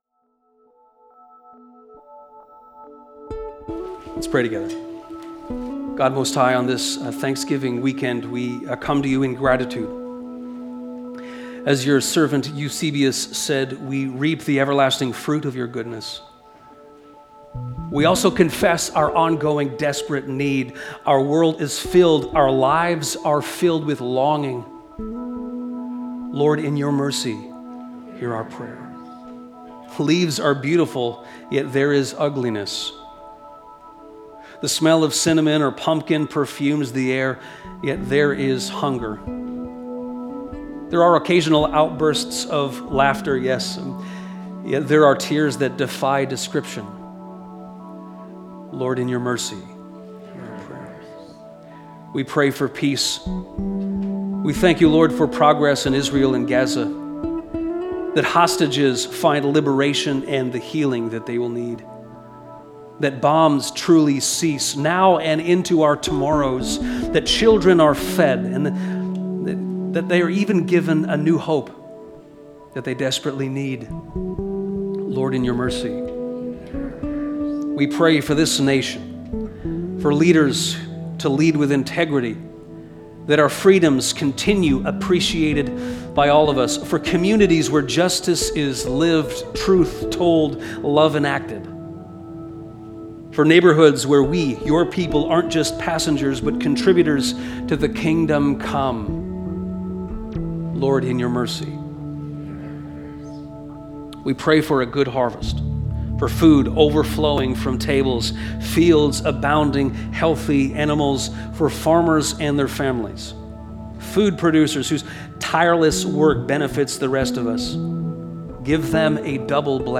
Join in with this prayer from our Thanksgiving service on October 12, 2025.